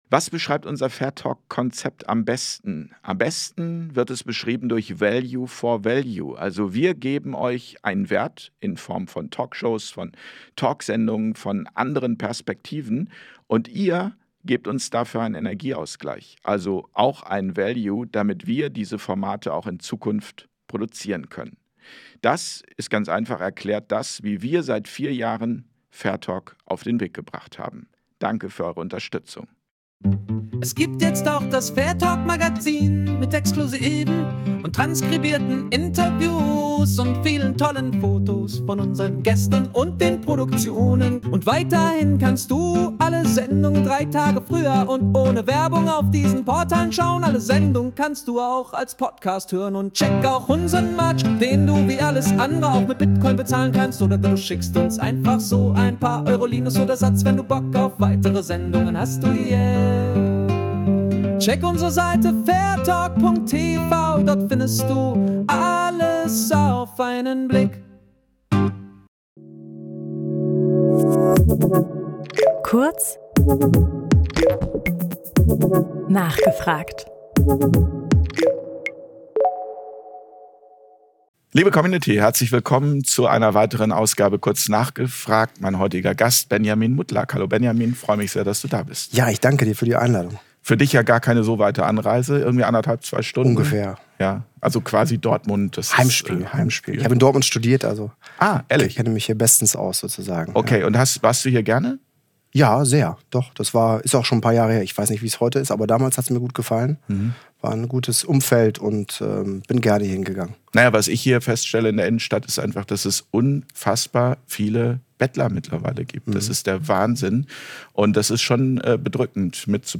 Das Interview Format